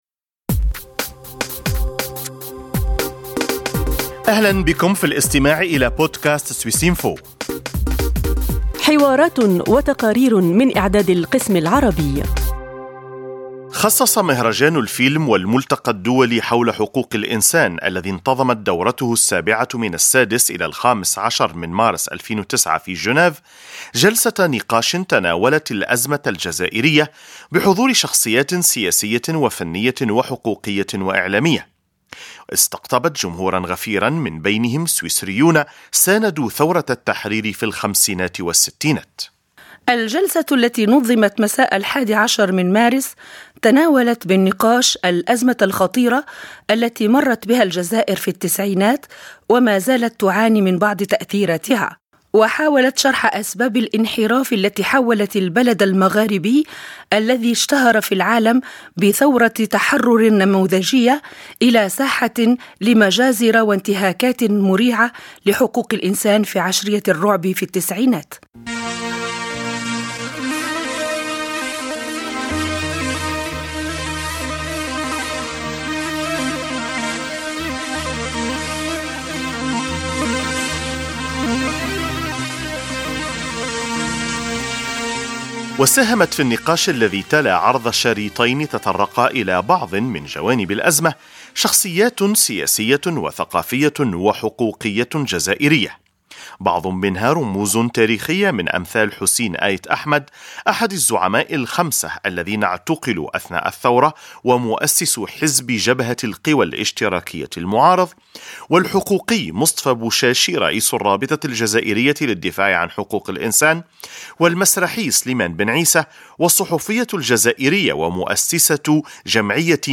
حديث